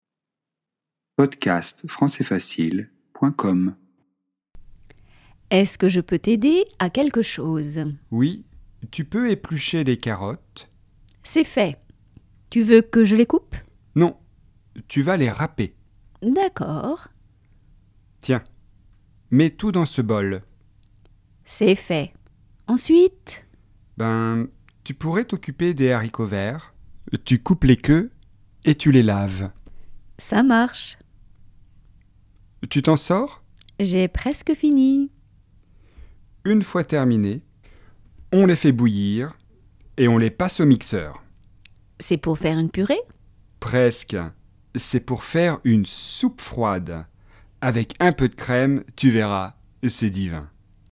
🔷DIALOGUE :